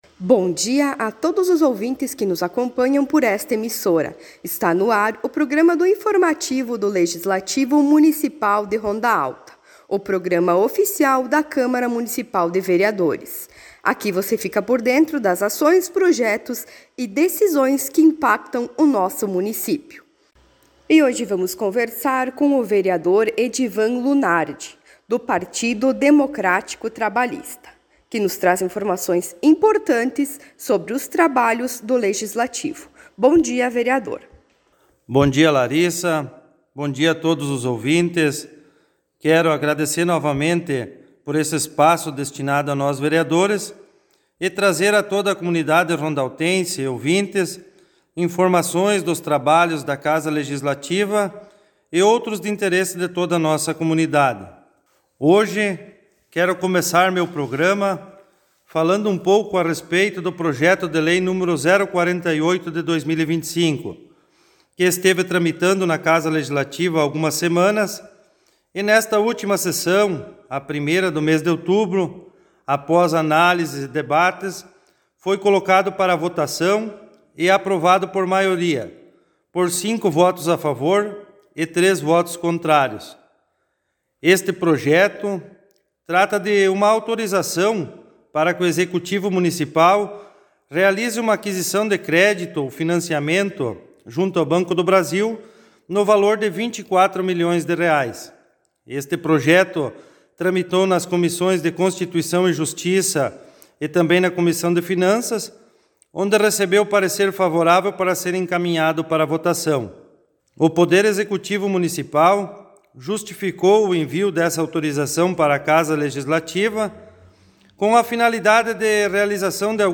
Informativos radiofônicos